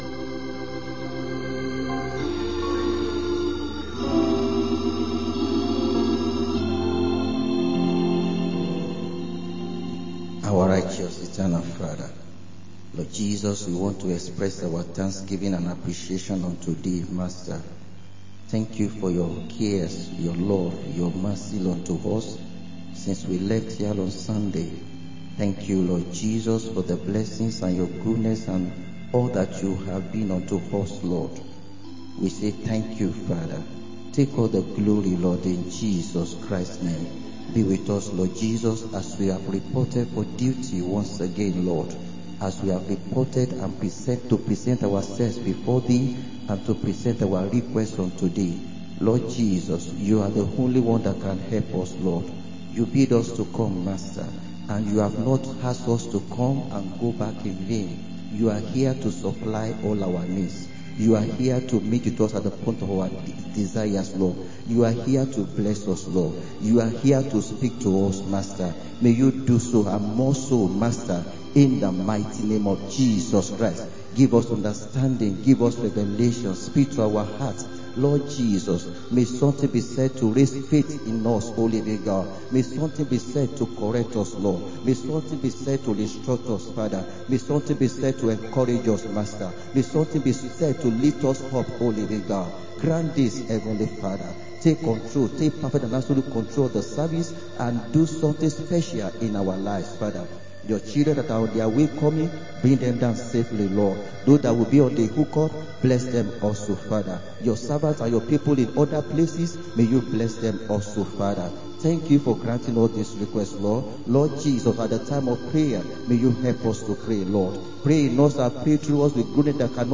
Wed. Prayer meeting